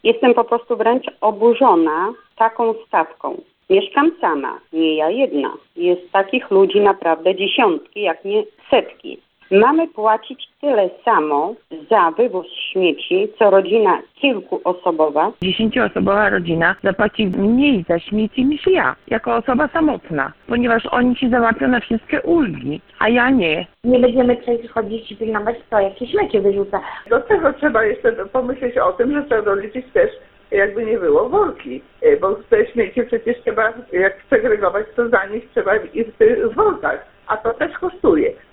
Takimi opiniami dzielą się z nami już od wczoraj nasi słuchacze.